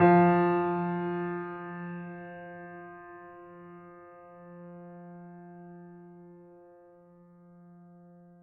piano-sounds-dev
Steinway_Grand